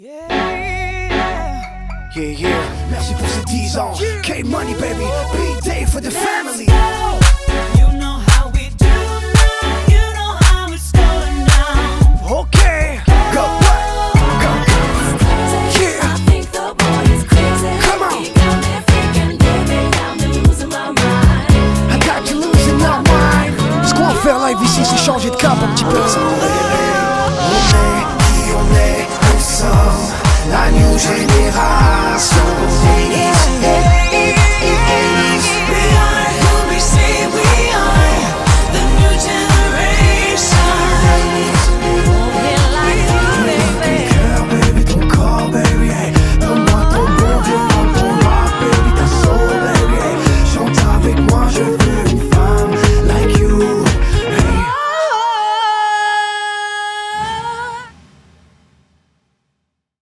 pop urbaine francophone